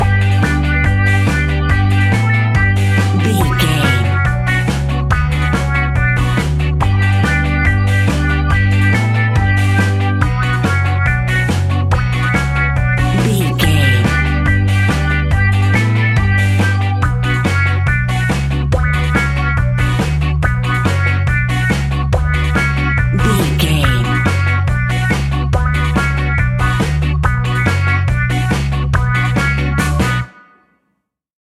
Classic reggae music with that skank bounce reggae feeling.
Aeolian/Minor
reggae
reggae instrumentals
laid back
chilled
off beat
drums
skank guitar
hammond organ
percussion
horns